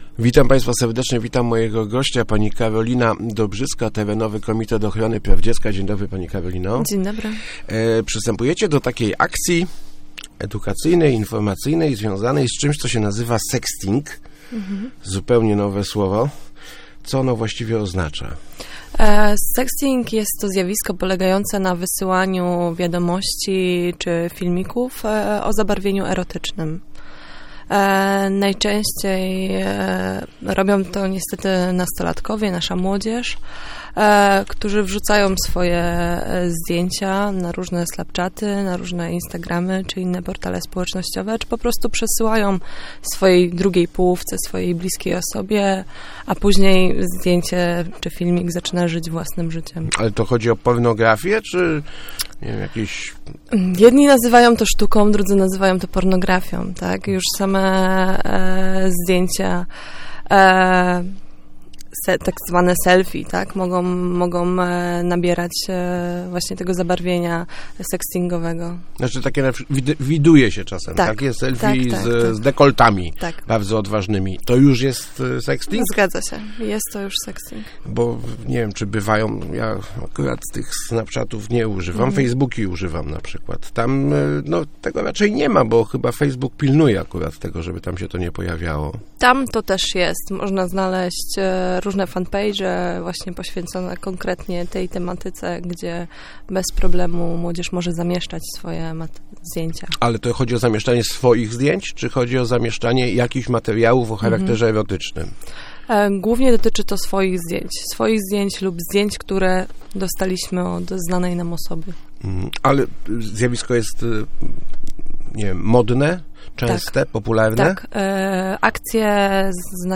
mówiła w Rozmowach Elki